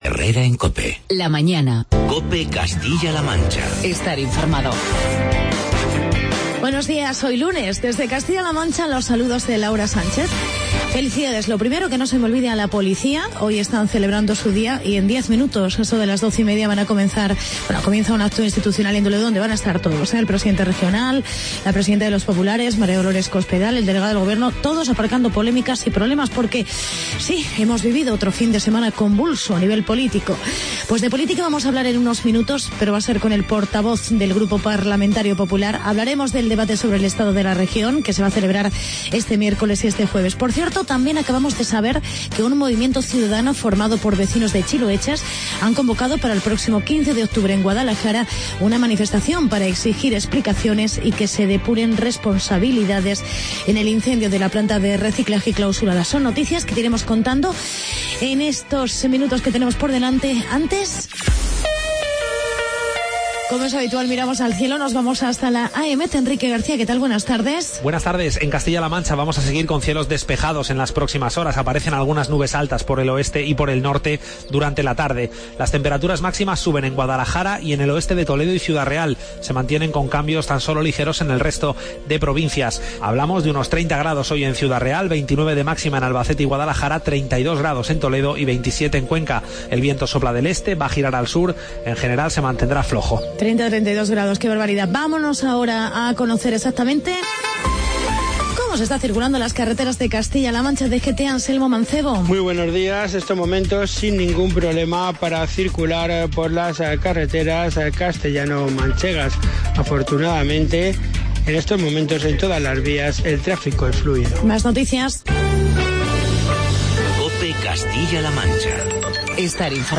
Actualidad y entrevista con Francisco Cañizares, portavoz del Grupo Parlamentario Popular en las Cortes de CLM.